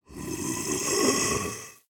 1.21.4 / assets / minecraft / sounds / mob / husk / idle2.ogg